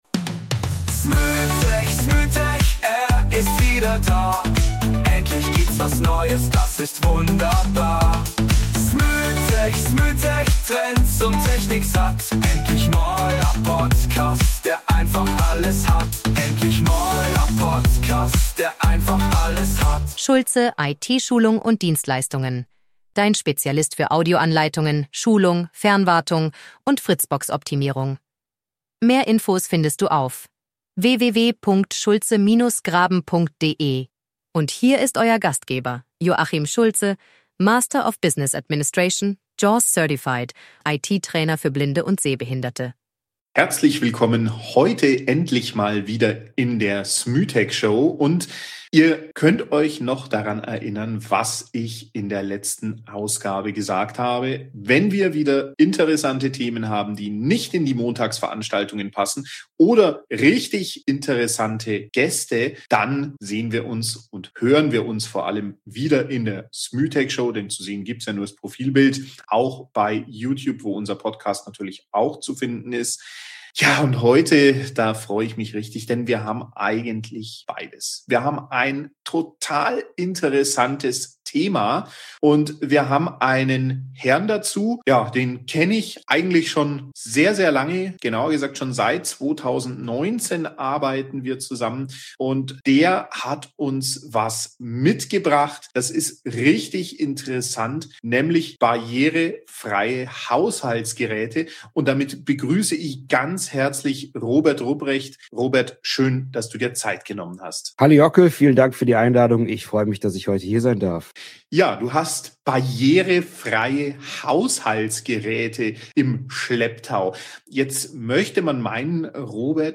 **Was du in dieser Episode lernst:** - Wie du **bestehende Geräte** mit fühlbaren Symbolen, Sensor-Knöpfen und einem Lautsprecher zum Sprechen bringst – in nur 10–15 Minuten - Live-Demos: Waschprogramme + Pflegetipps per Klopfen, Herd-Temperatur auf 10 Grad genau, Mikrowelle mit echten Rezepten (Milchreis, Schweinekotelett, Teewasser etc.)